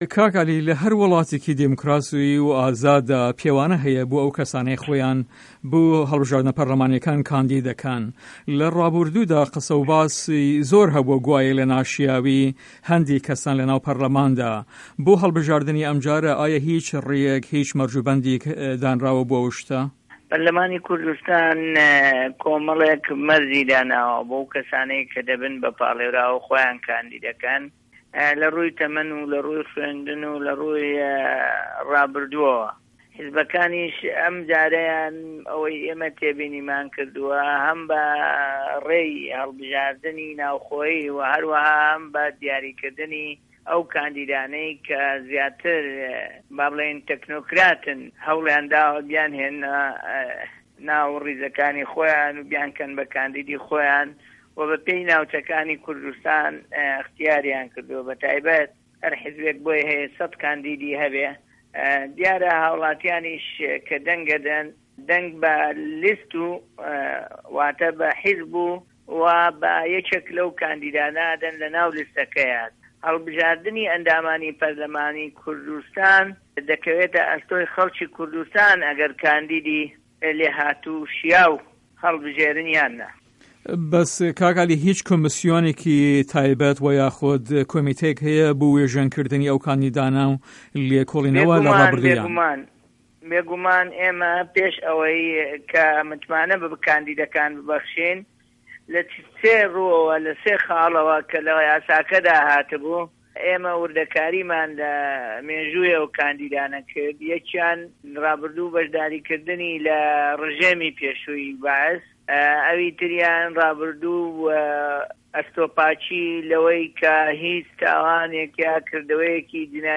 عه‌لی قادر به‌رێوه‌به‌ری کمسیۆنی هه‌ڵبژاردنه‌کان له‌ هه‌رێمی کوردستان له‌ هه‌ڤپه‌ێڤینێکدا له‌گه‌ڵ به‌شی کوردی ده‌نگی ئه‌مریکا ده‌ڵێت" په‌رله‌مانی کوردستان کۆمه‌ڵێک مه‌رجی داناوه‌ بۆ ئه‌و که‌سانه‌ که‌ ده‌بن به‌ پاڵێۆراو و خۆیان کاندید ده‌که‌ن له‌ رووی ته‌مه‌ن، خوێندن و له‌ رووی رابووردوه‌وه‌ و هه‌ر پارت مافی کاندیکردنی 100 که‌سی هه‌یه‌.